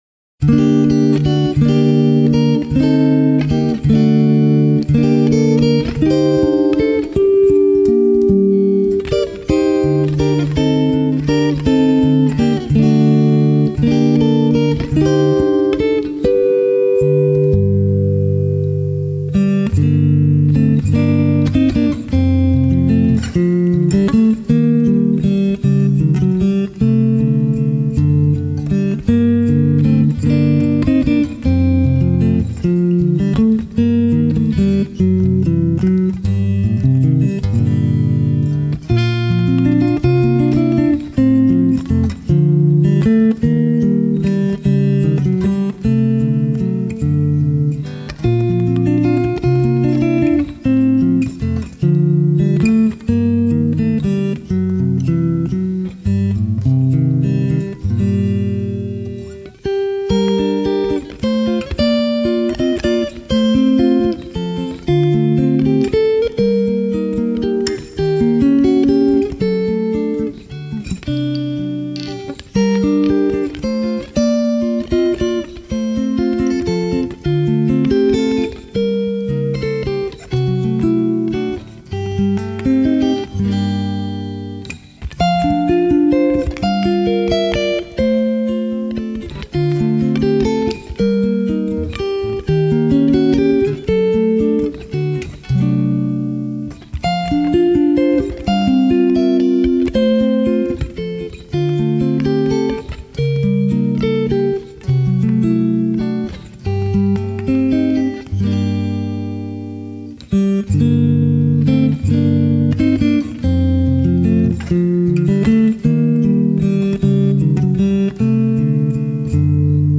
类型:Newage